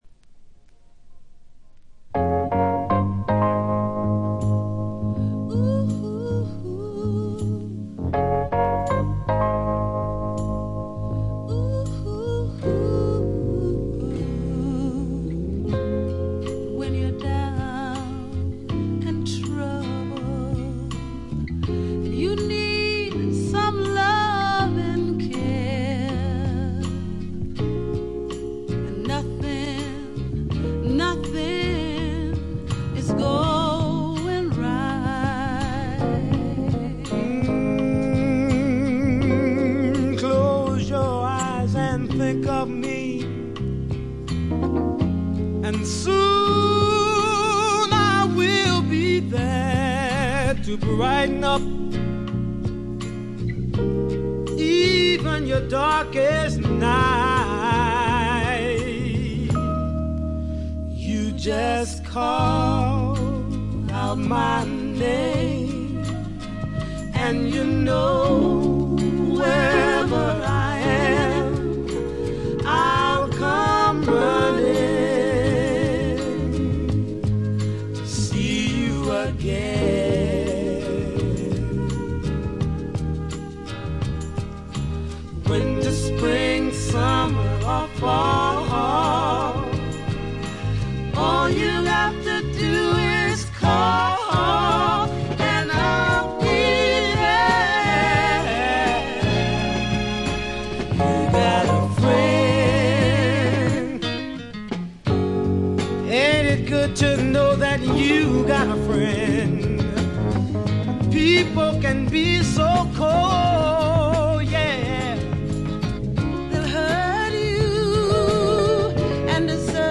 部分試聴ですが、ほとんどノイズ感無し。
頂点を極めた二人の沁みる名唱の連続で身体が持ちませんね。
試聴曲は現品からの取り込み音源です。